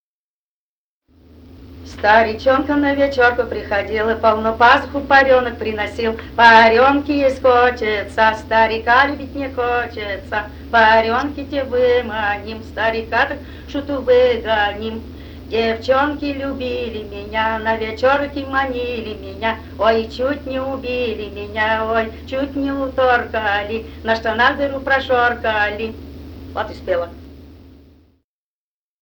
Этномузыкологические исследования и полевые материалы
Пермский край, г. Оса, 1968 г. И1074-10